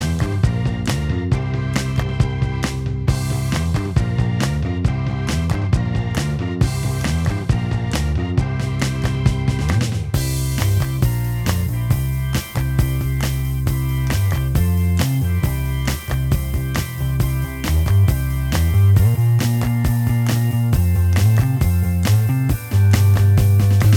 Minus Guitars Soft Rock 2:25 Buy £1.50